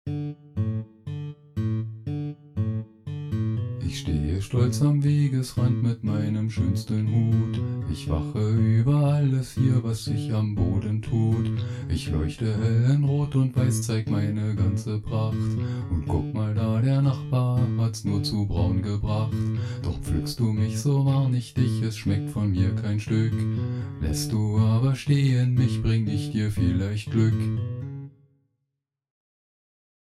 Kinderlied: Der Fliegenpilz
Ich hab das Lied mal auf die Schnelle gesungen (anhören auf eigene Gefahr!!), passt doch problemlos in den Rythmus - ?